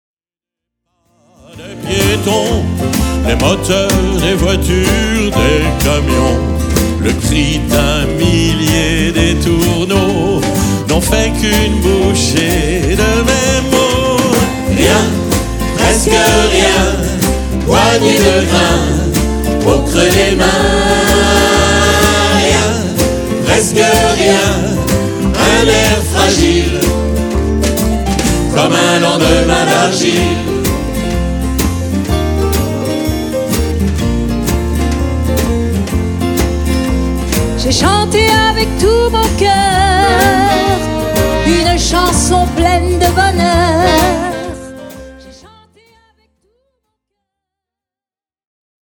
en public.
choeurs
à la guitare et aux sax
violoncelle
Format :MP3 256Kbps Stéréo